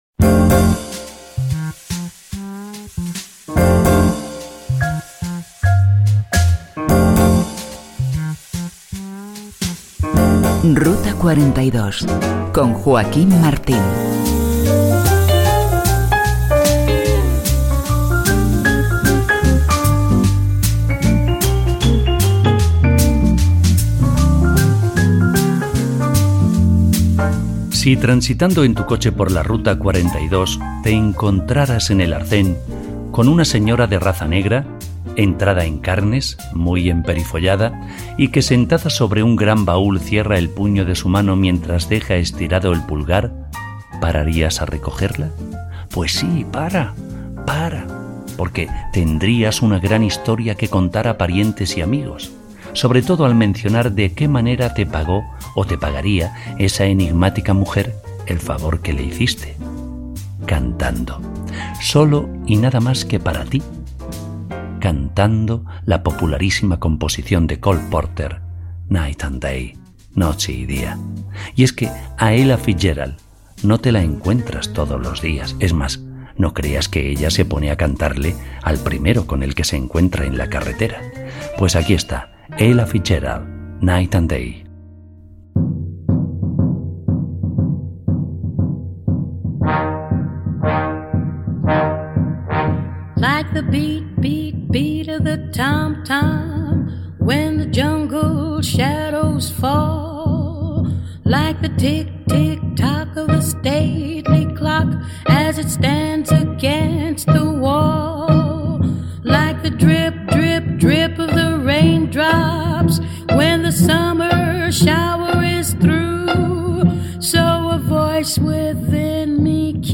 Careta del programa, presentació, tema musical, indicatiu, comentari sobre el nou tema musical
Musical